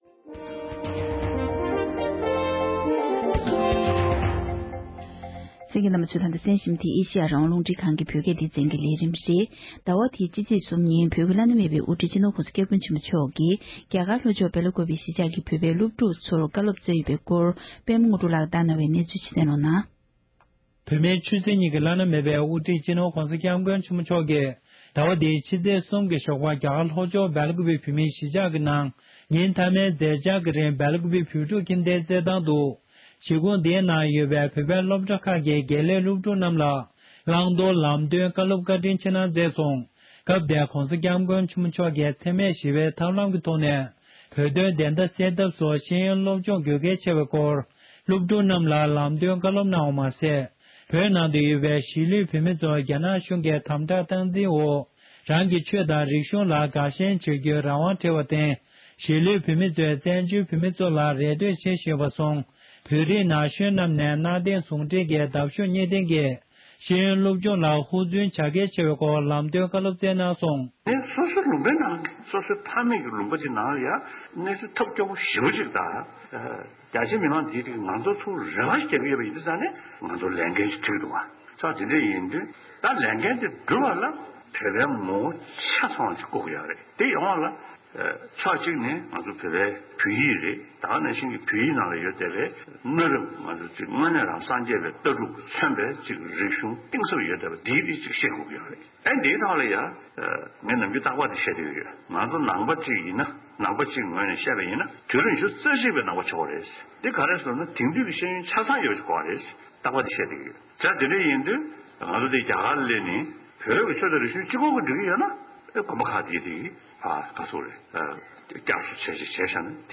ས་གནས་ས་ཐོག་ནས་བཏང་འབྱོར་བྱུང་བའི་གནས་ཚུལ་ཞིག་ལ་གསན་རོགས༎